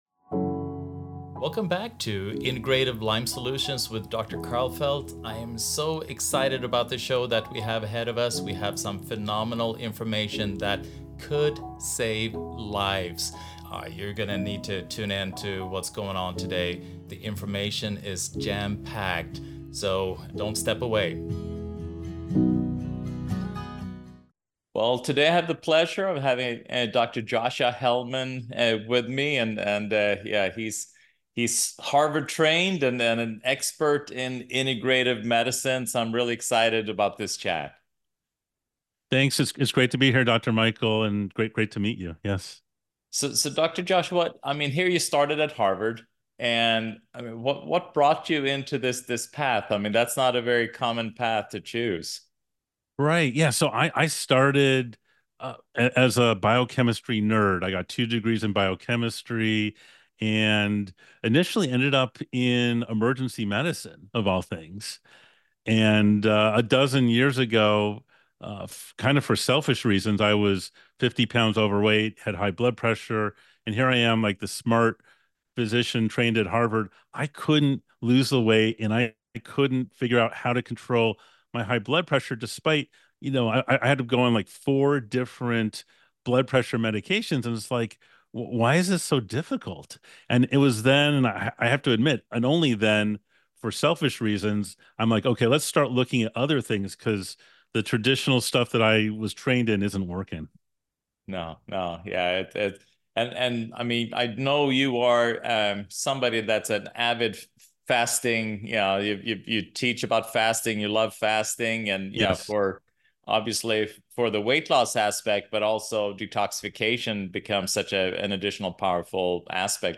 Integrative Medicine, Fasting, and Neurological Health: A Conversation